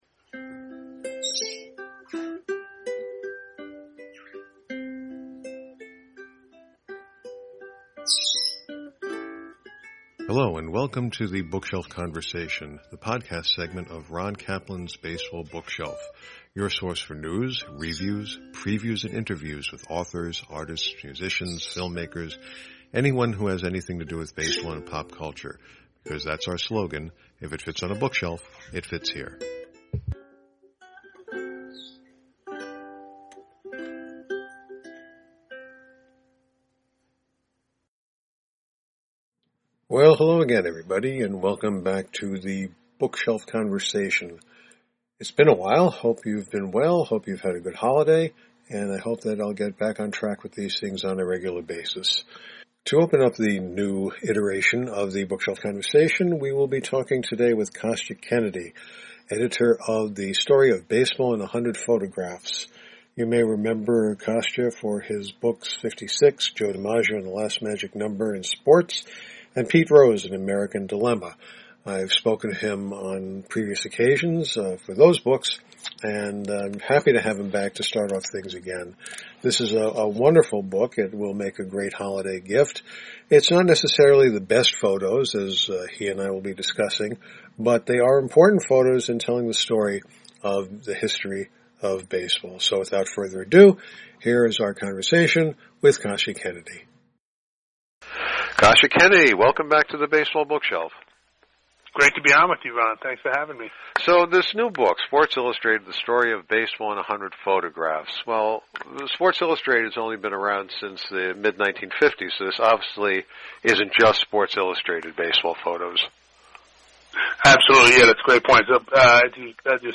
The Bookshelf Conversation